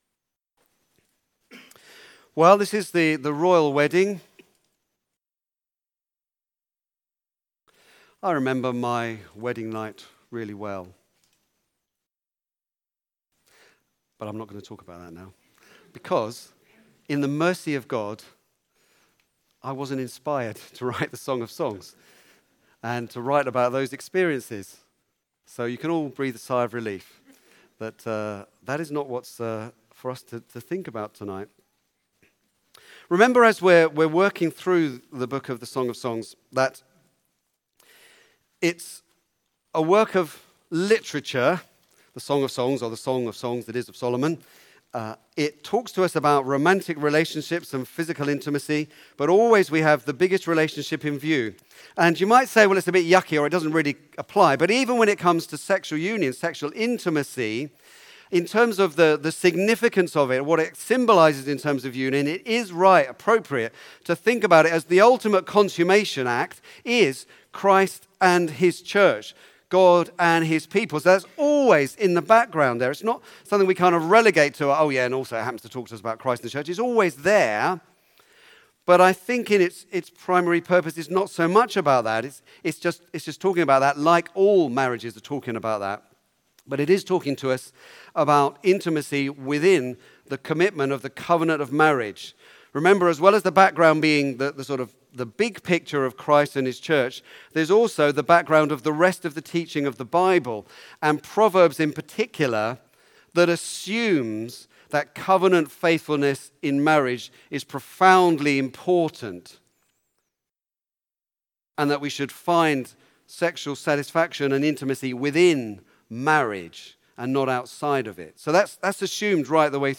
Back to Sermons Royal Wedding